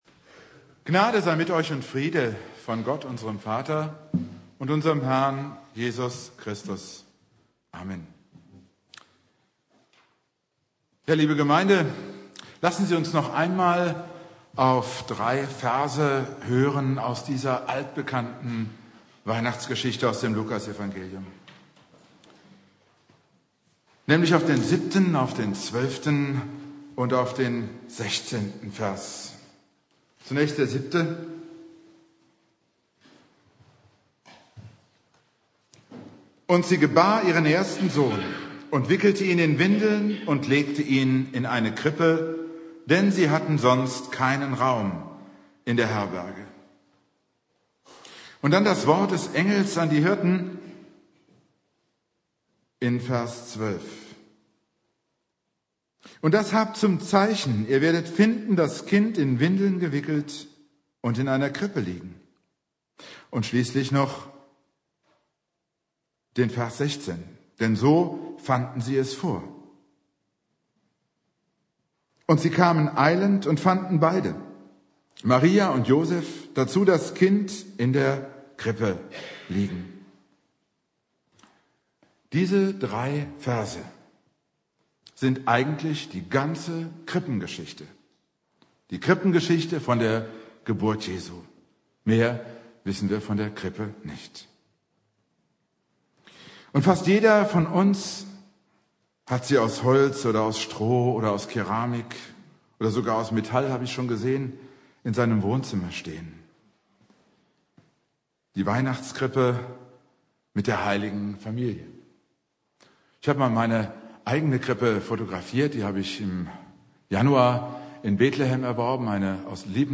Predigt
Heiligabend